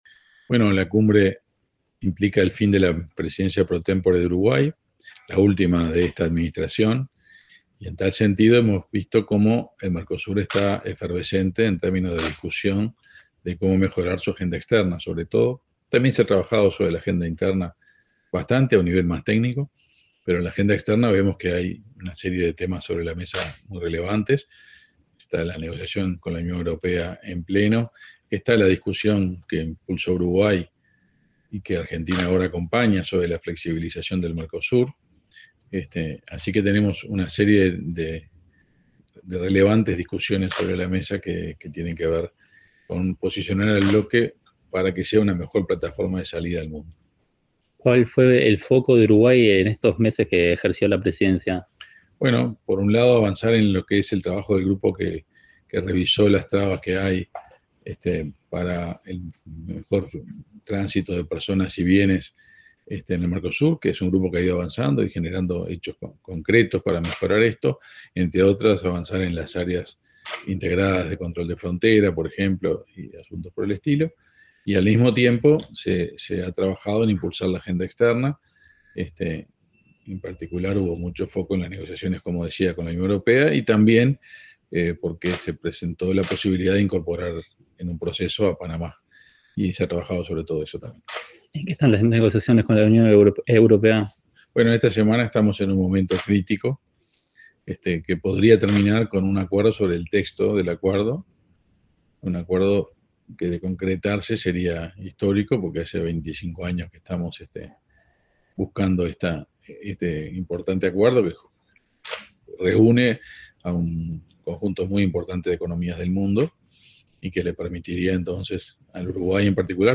Entrevista al canciller, Omar Paganini